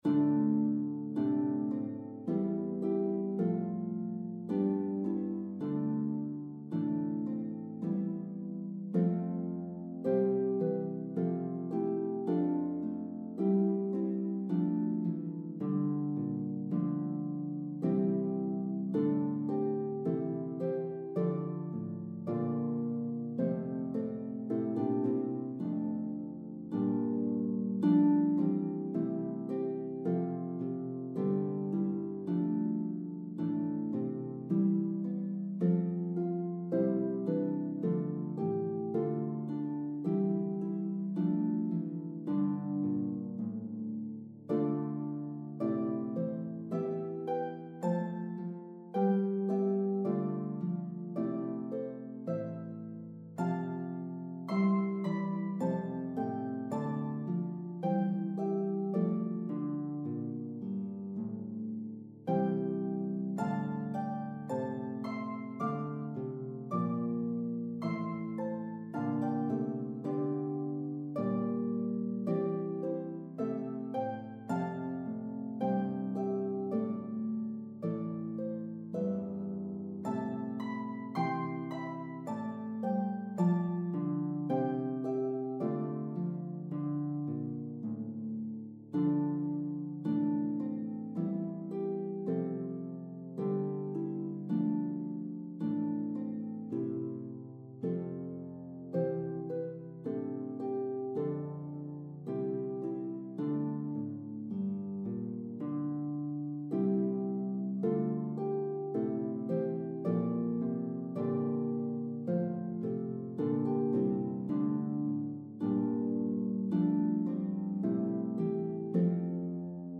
This is an Early Intermediate Arrangement.